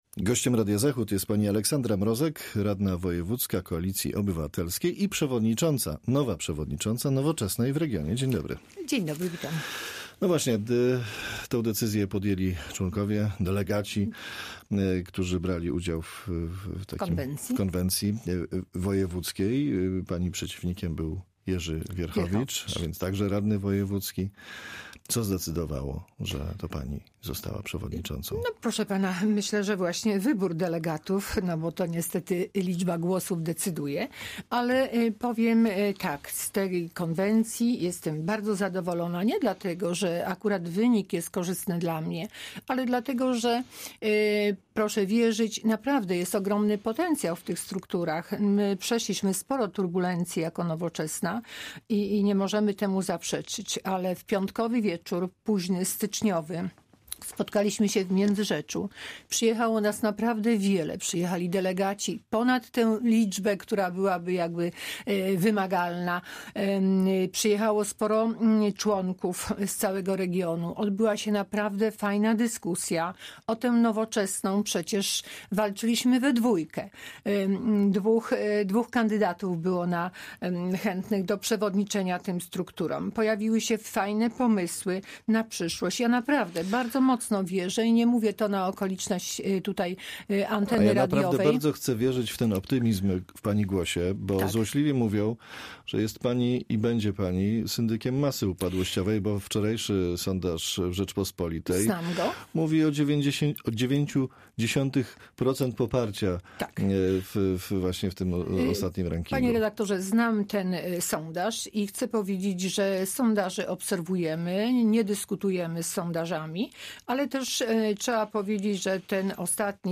Z radną sejmiku wojewódzkiego (Koalicja Obywatelska) i przewodniczącą Nowoczesnej w regionie rozmawia